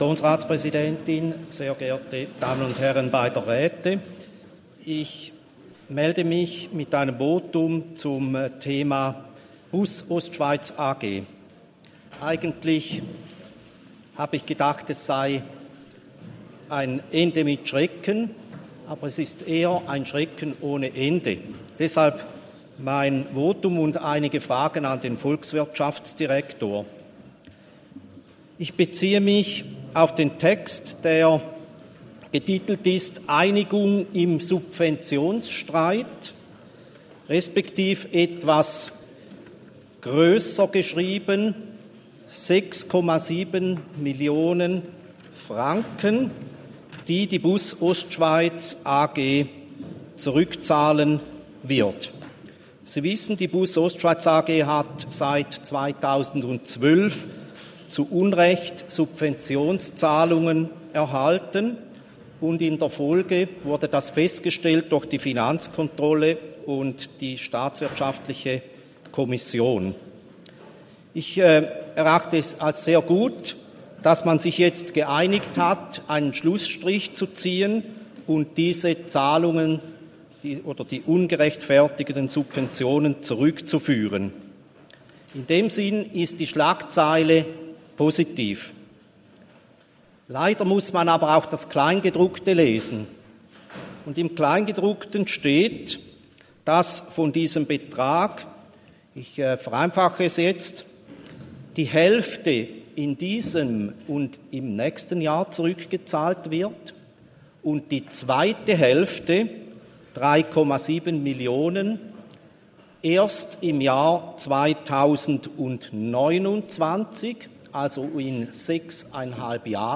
Session des Kantonsrates vom 12. bis 14. Juni 2023, Sommersession
12.6.2023Wortmeldung